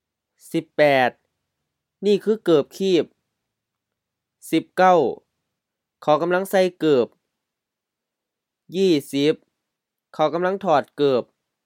เกิบคีบ gə:p-khi:p LF-HF รองเท้าแตะ flip-flop, sandal
กำลัง gam-laŋ M-HR กำลัง auxiliary indicating continuous or progressive action